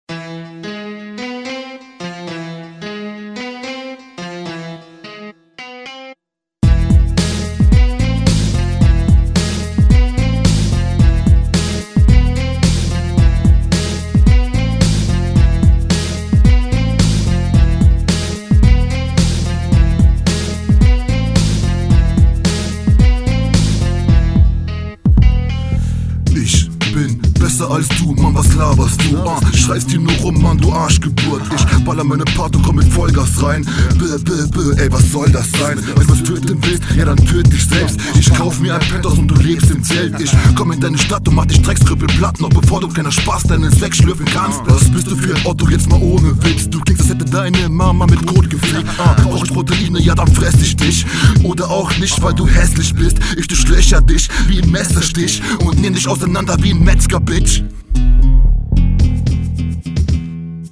Der Flow auf dem Beat geht gar nicht.
ey was soll das sein? leider offbeat, reime sind shamy, qualität lässt zu wünschen, aber …